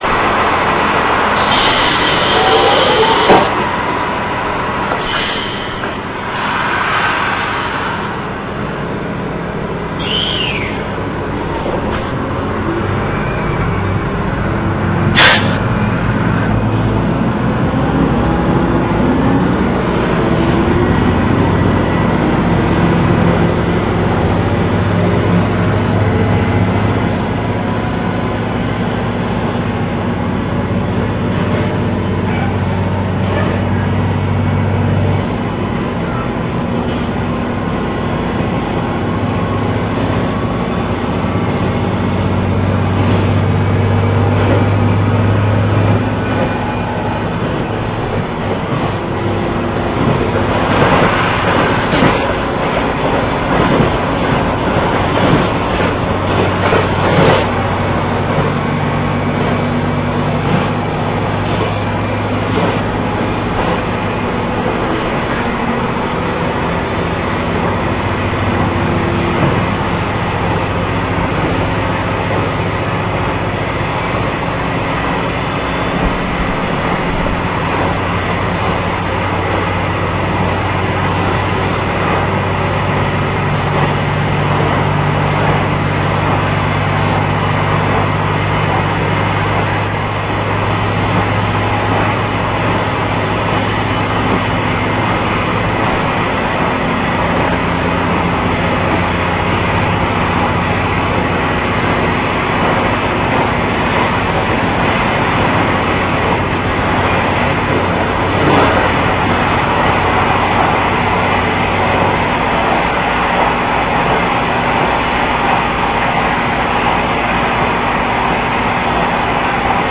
加速音[k283a.ra/RealAudio5.0形式/394KB]
収録区間：室蘭本線・千歳線 苫小牧→南千歳(スーパー北斗21号にて)
エンジン：N-DMF11HZA(355PS)×2
※変速のつなぎ目が分かりづらいですが、最終段の直結4段まで入っていると思います。